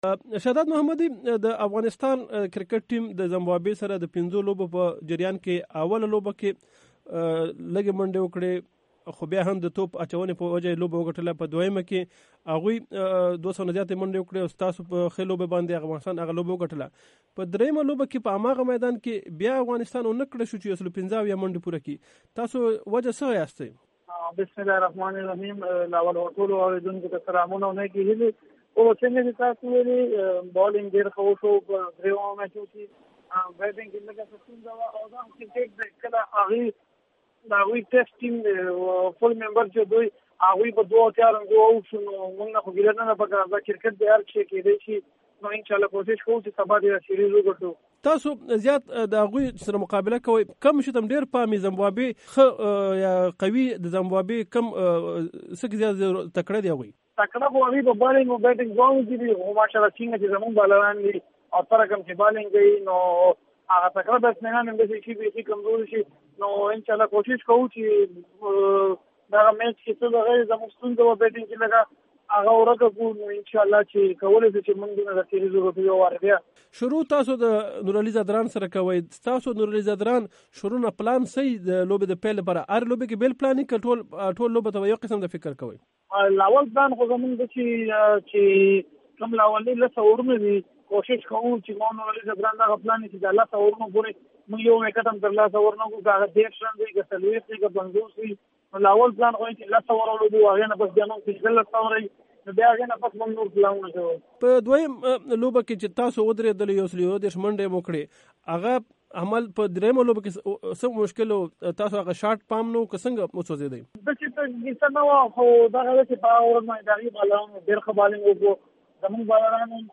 محمد شهزاد محمدي د امریکا غږ آشنا راډیو سره مرکه کې ویلي چې دوي د خپلې درېمې لوبې د نتیجې نه ډیر څه زده کړل او د زمبابوې خلاف د راتلونکو سیالیو دپاره ېې راویښ کړي وو.